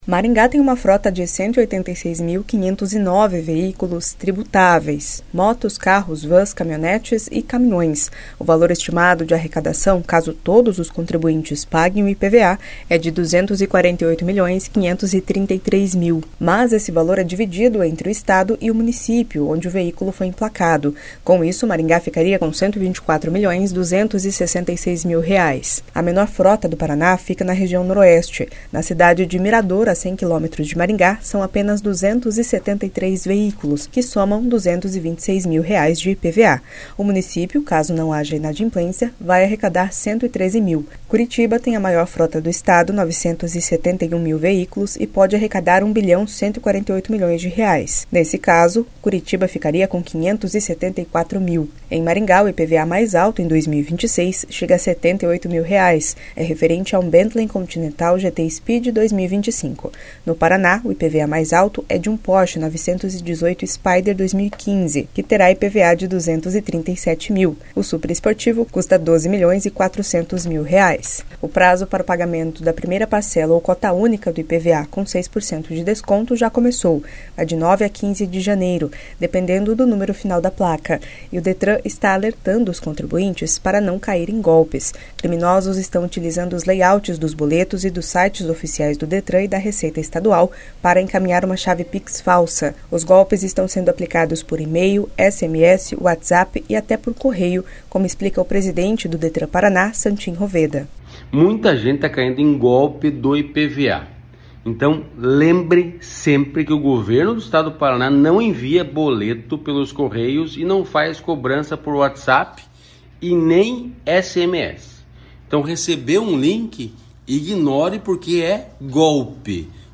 Criminosos estão utilizando os layouts dos boletos e dos sites oficiais do Detran-PR e da Receita Estadual para encaminhar uma chave pix falsa. Os golpes estão sendo aplicados por e-mail, SMS, WhatsApp e até por Correio, como explica o presidente do Detran PR, Santin Roveda.